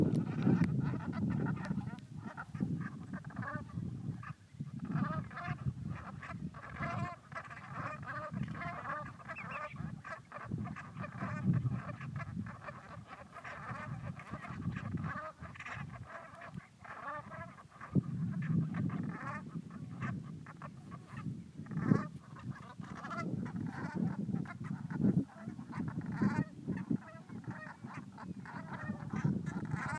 Pacific Black Brant Geese
Out on the salt marshes of Guerrero Negro, Baja California Sur, we encountered a large flock of these small, gregarious geese.
Geese-Guerrero-Negro.m4a